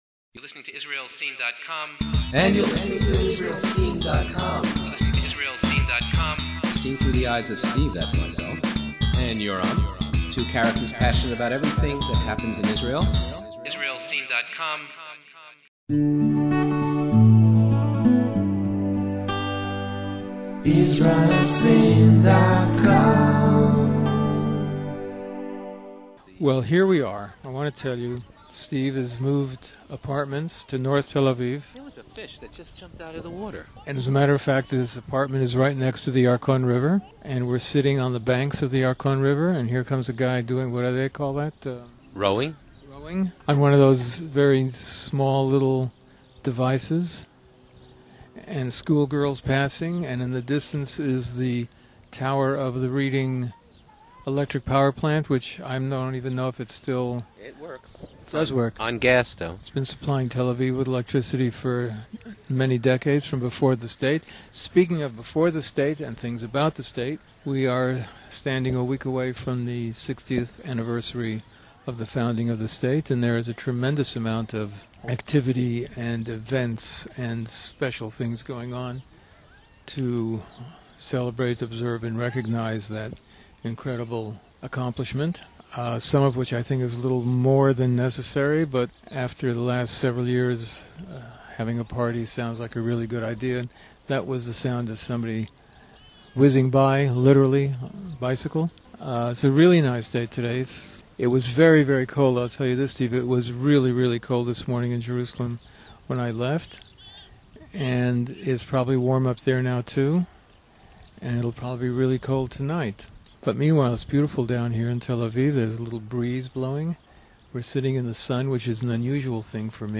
Share Their Views from the Park HaYarkon in Tel Aviv
discuss the topics of the day from the Park HaYarkon in North Tel Aviv.